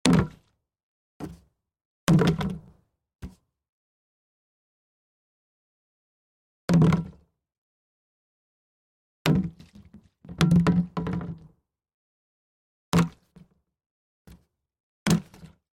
随机 " 塑料气体容器填充物
描述：塑料气体容器fill.wav
Tag: 塑料 气体 填充 容器